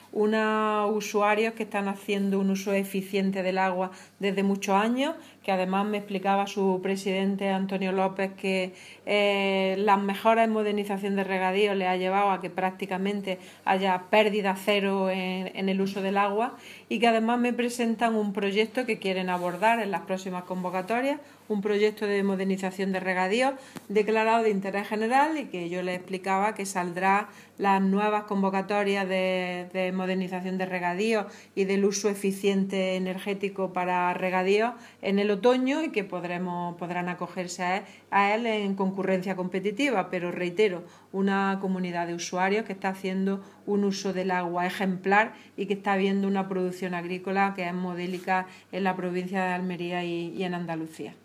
Declaraciones de Carmen Ortiz sobre la Comunidad de Usuarios de Aguas de la Comarca de Níjar (Almería)